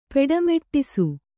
♪ peḍa meṭṭisu